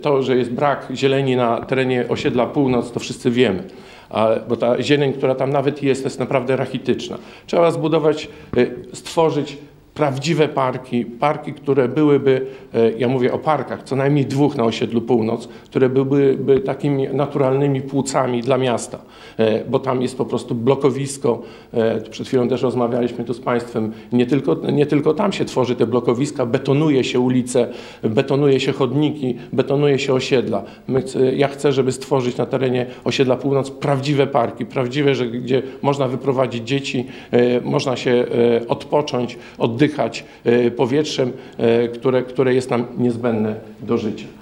Lepszą ochronę środowiska obiecywał w czwartek (27.09.18) na konferencji prasowej